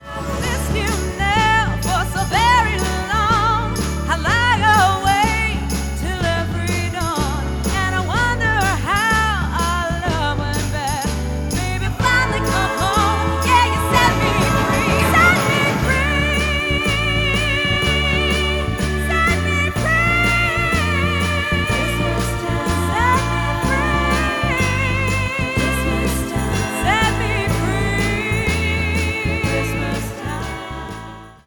Motown/Soul